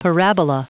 Prononciation du mot parabola en anglais (fichier audio)